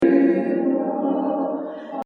sur certaines fréquences on entend clairement une sorte de chorus ou flanging ! et les voix sont quand-même un peu dénaturées dans l'ensemble - en tout cas le fichier "choeur" (les voix toutes seule) contient pas mal d'artefacts et mauvaises résonances tout le long; et on l'entend très fortement sur les fins de phrase et à la respiration des chanteurs/euses (résonance genre boite de conserve)
et puis tu verras qu'une fois que tu as "repéré" les "colorations flanging" on les entend clairement tout le long de l'enregistrement... ces colorations sont dues aux déphasages
c'est sans doute dû à l'utilisation et emplacement de nombreux micros et leur différences de qualité (mais aussi dû en partie à la reverbe du lieu)